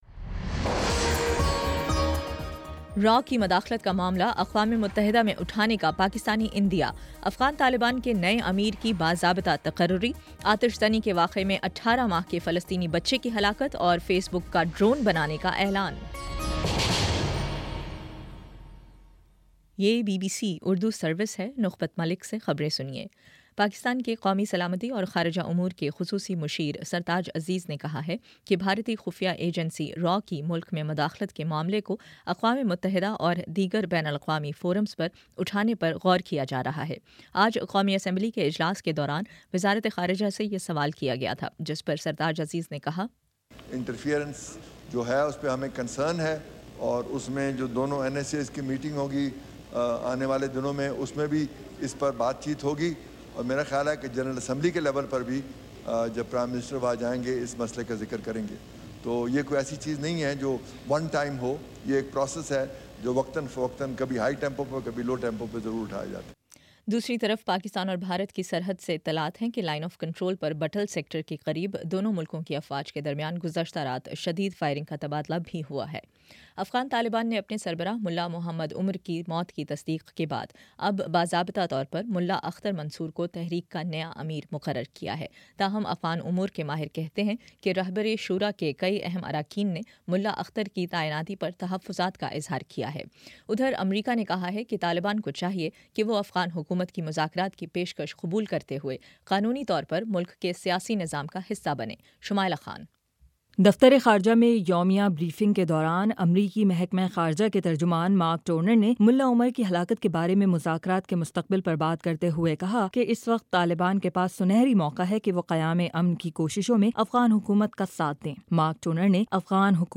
جولائی 31: شام چھ بجے کا نیوز بُلیٹن